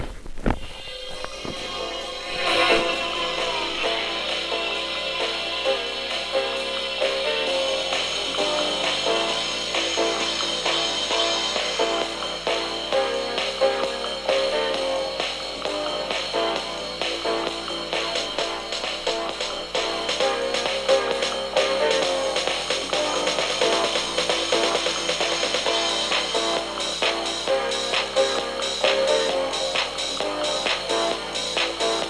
I heard this on the radio tonight but i know i heard it b4,